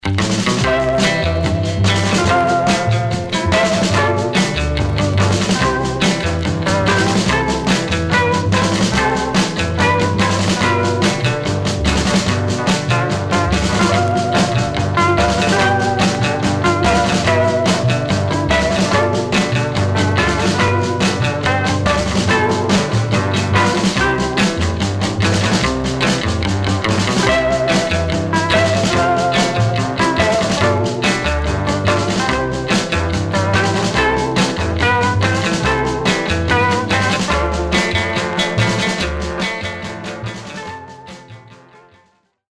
Stereo/mono Mono
R & R Instrumental